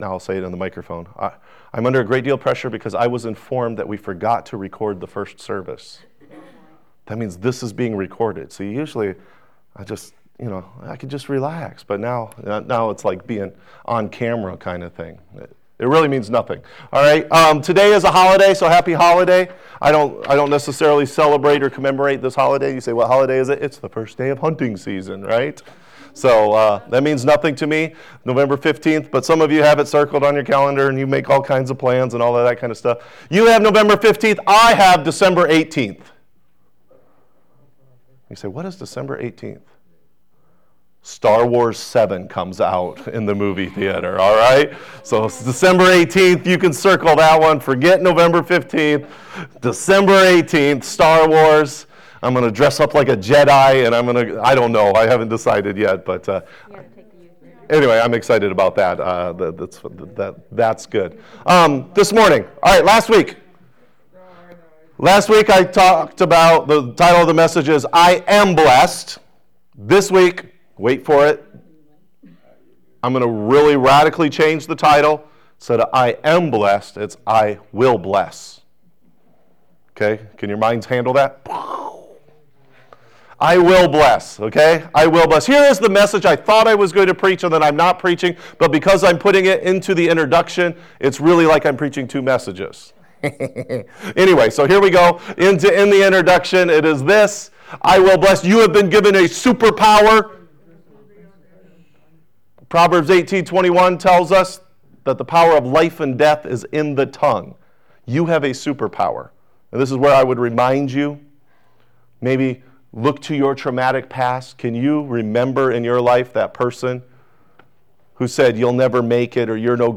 Sermon Archive - Life Worship Center